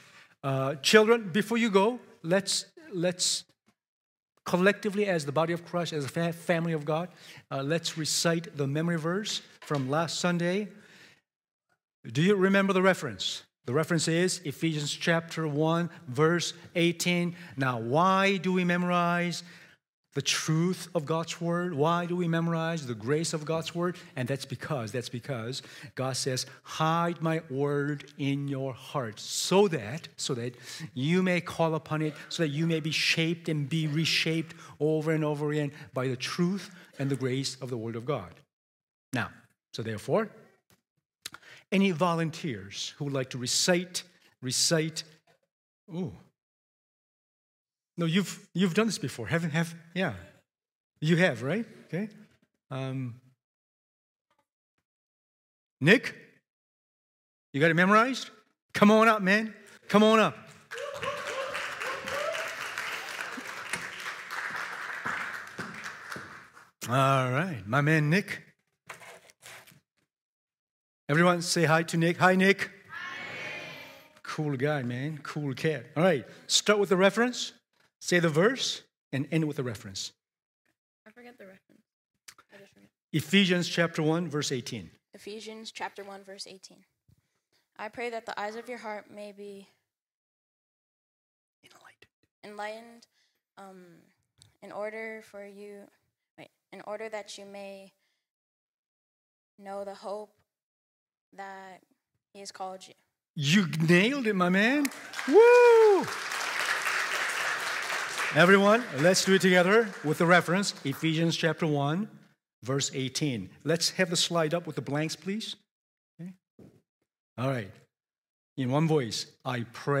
Sermons | mosaicHouse